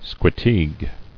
[sque·teague]